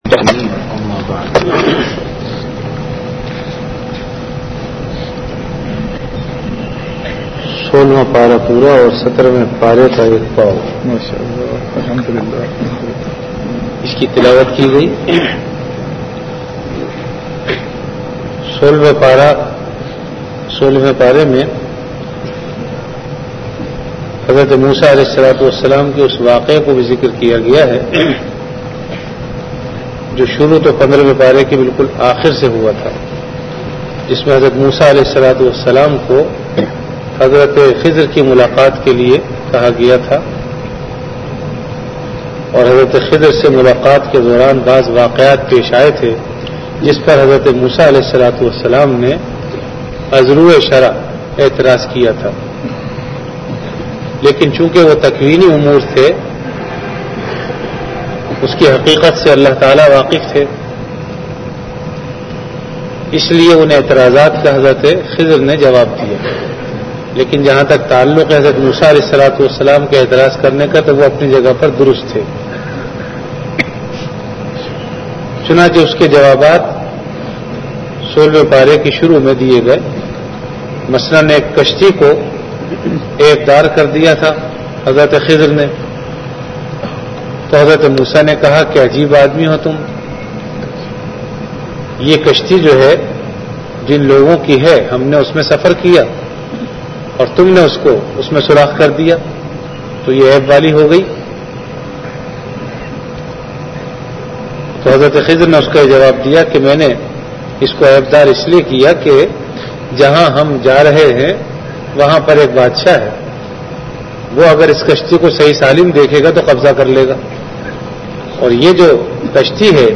An Islamic audio bayan
Delivered at Jamia Masjid Bait-ul-Mukkaram, Karachi.
Ramadan - Taraweeh Bayan · Jamia Masjid Bait-ul-Mukkaram, Karachi